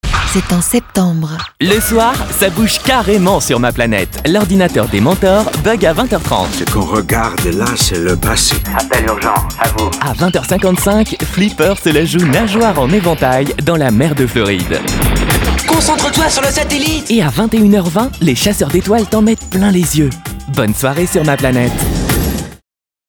MA PLANETE sommaire, junior - Comédien voix off
Genre : voix off.